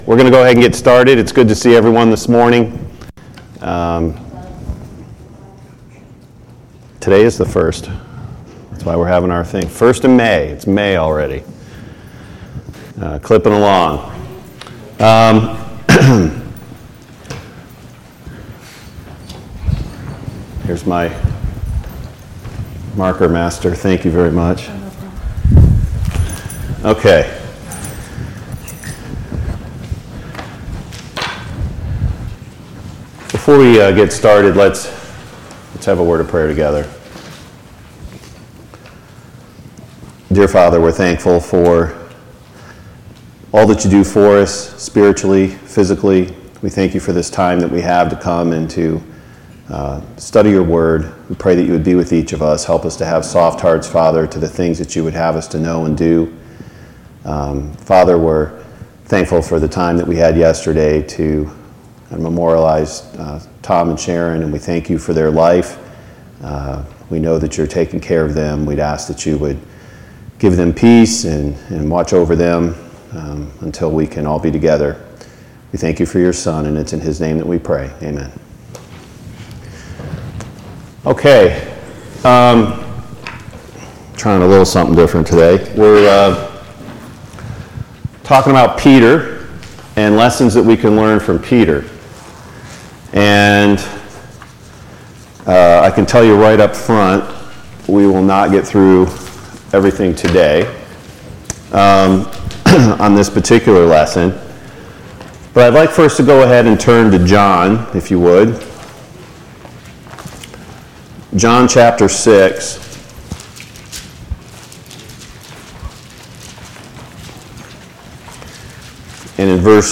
Sunday Morning Bible Class Topics